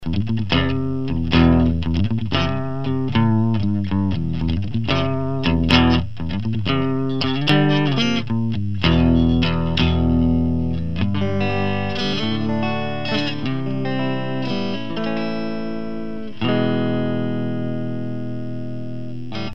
Четыре режима-звучания - lead-high, lead-low, clean-high, clean-low.
Примеры звучания в кабинет с Celestion Rocket50, микрофон genius в микрофонный вход звуковой карты, без обработок.
sovtek_engl_clean_low.mp3